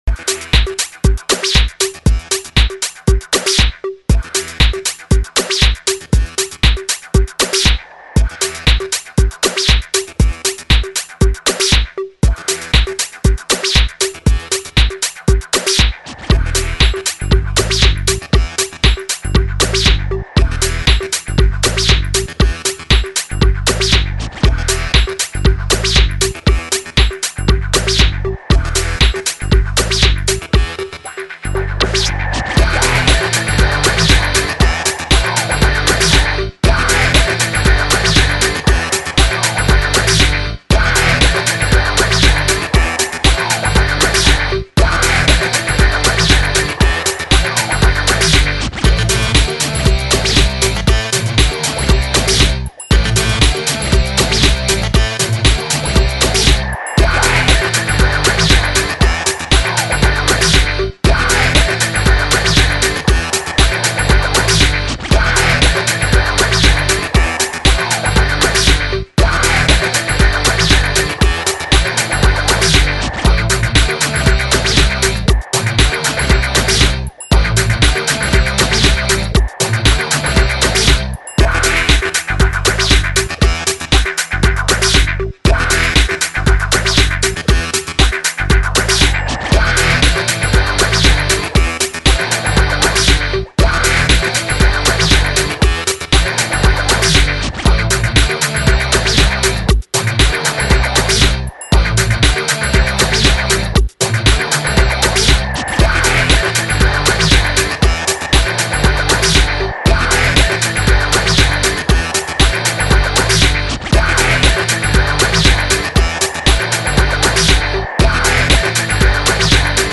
Hard Guitar Electronica